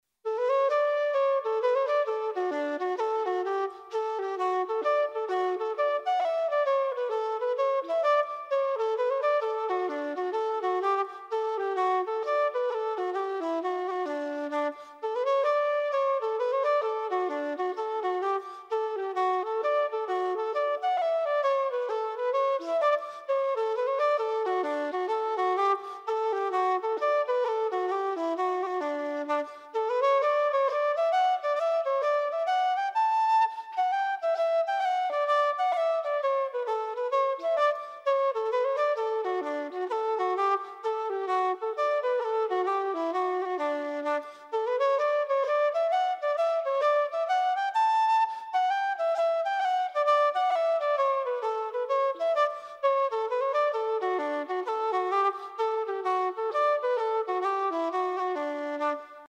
Traditional Irish Music - learning resources
Simple Audio -- Piano (mp3)